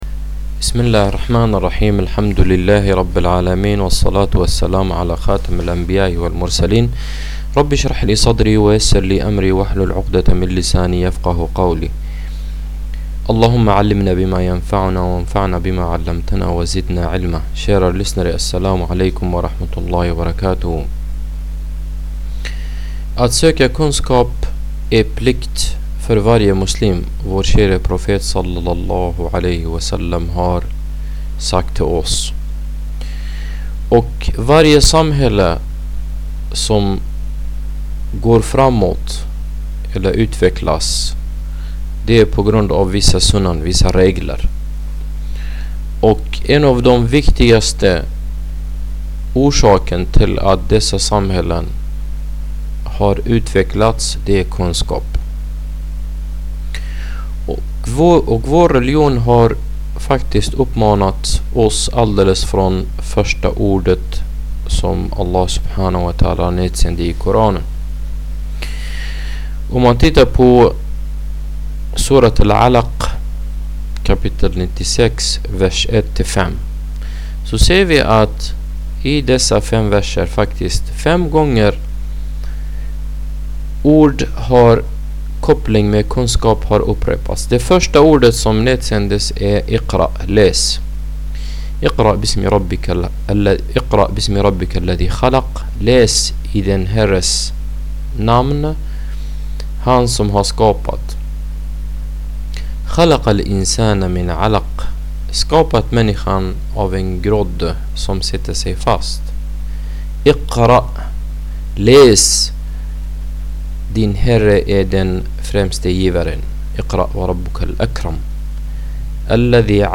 En föreläsning om islams syn på kunskap